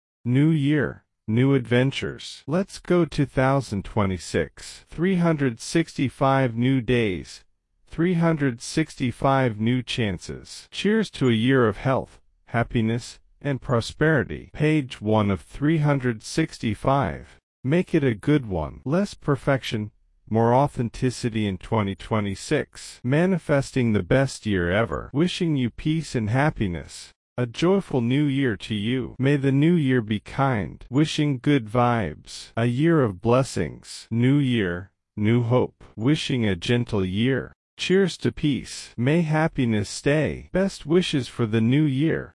Nhấn ngay vào link MP3 bên dưới để nghe cách nhấn nhá, ngữ điệu “sang chảnh” của người bản xứ cho các mẫu câu trên.